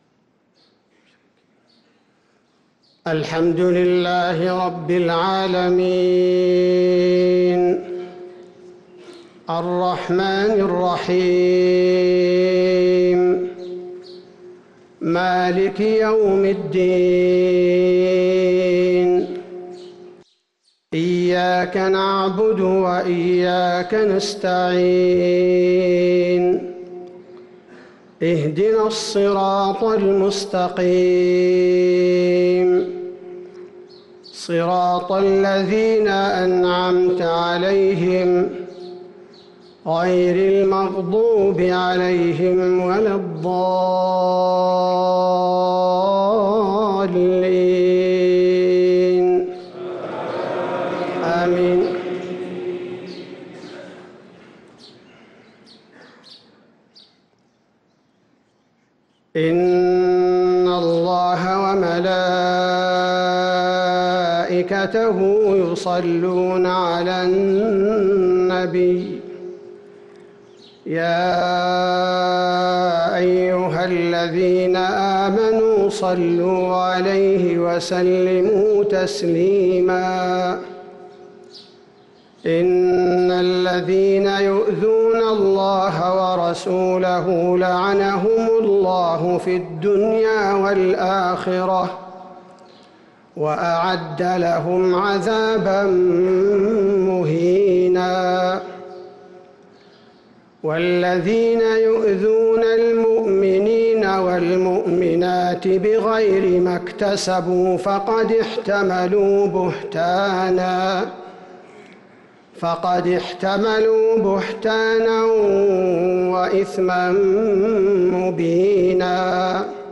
صلاة الفجر للقارئ عبدالباري الثبيتي 23 رمضان 1444 هـ